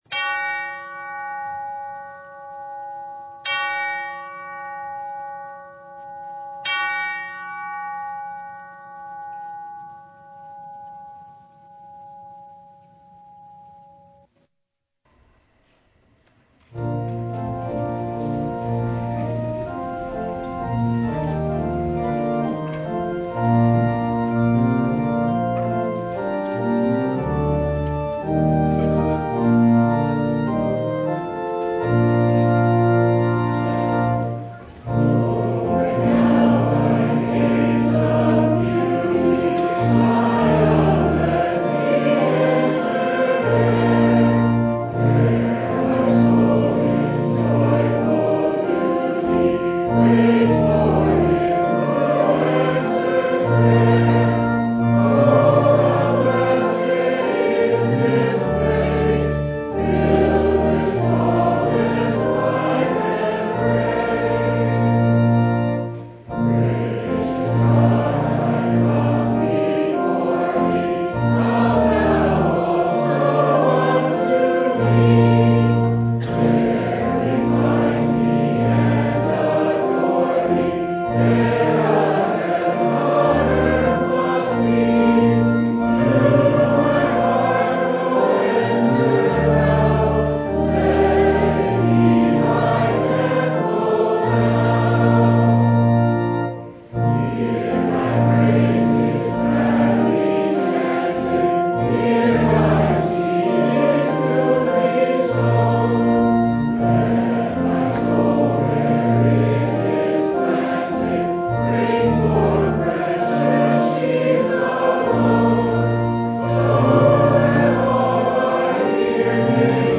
John 1:43-51 Service Type: Sunday Philip invited skeptical Nathanael to “come and see.”